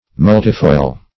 Search Result for " multifoil" : The Collaborative International Dictionary of English v.0.48: Multifoil \Mul"ti*foil\ (m[u^]l"t[i^]*foil), n. [Multi- + foil.]